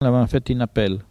Patois - archives
Catégorie Locution